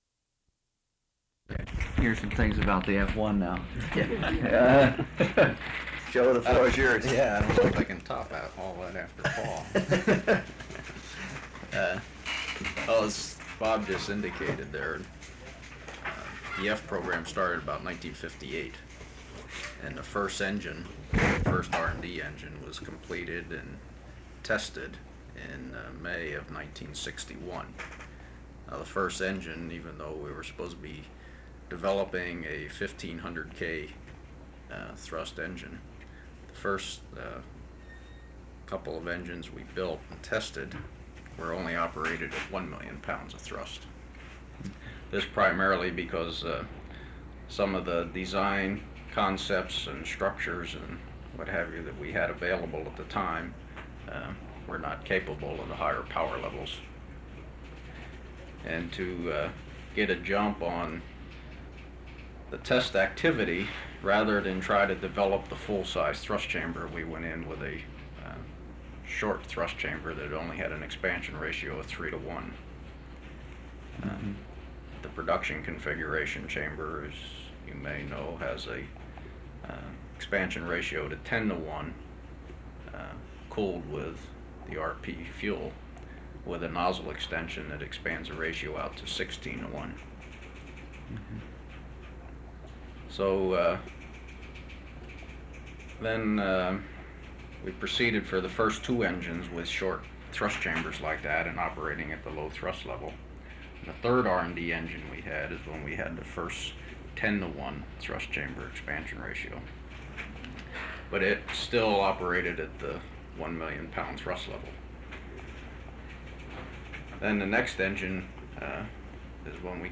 Description Interview with Rocketdyne engineers on rocket engine design and stability. Both sides of tape.
Relation clir_grant_audio_metadata Oral History Item Type Metadata Duration 1:03:18 Collection Saturn V Collection Tags Oral History Citation United States.